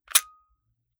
9mm Micro Pistol - Dry Trigger 001.wav